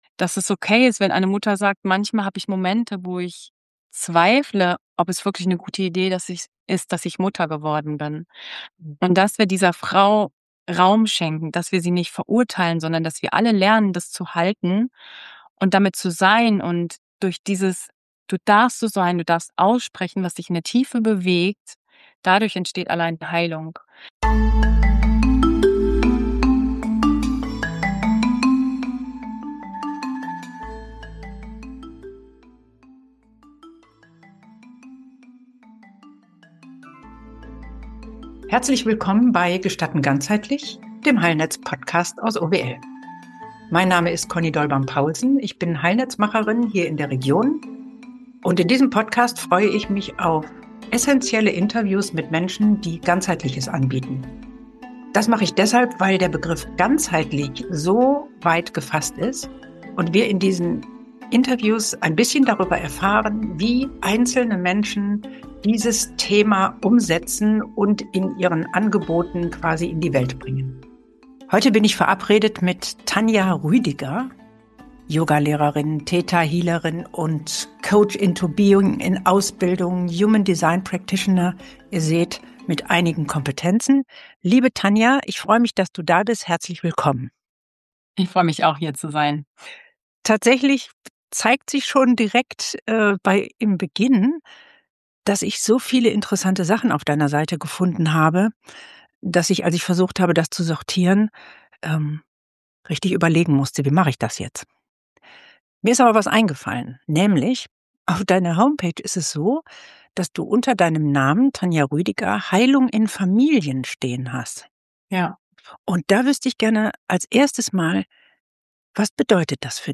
Hier hörst Du Interviews von Menschen, die im Heilnetz ganzheitliche Angebote machen und die im Podcast darüber sprechen, was ihnen dabei besonders wichtig...